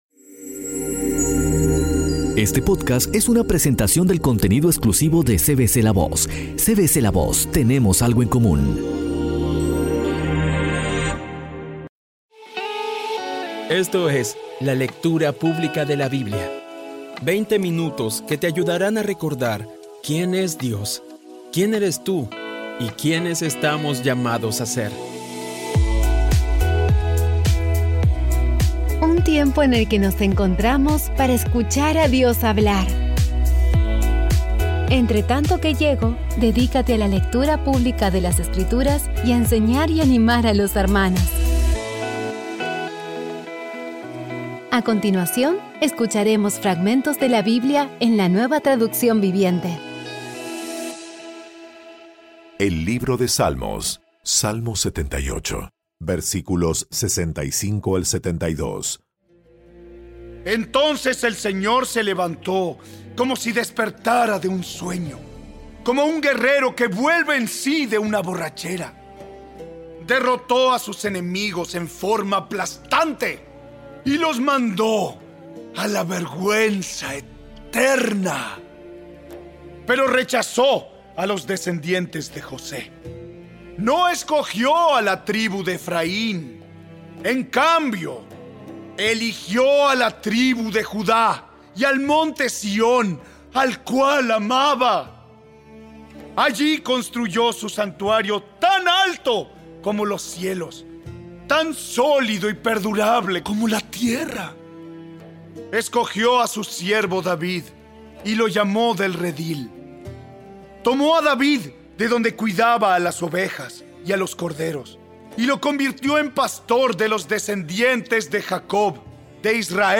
Sigue usando esta maravillosa herramienta para crecer en tu jornada espiritual y acercarte más a Dios. Poco a poco y con las maravillosas voces actuadas de los protagonistas vas degustando las palabras de esa guía que Dios nos dio.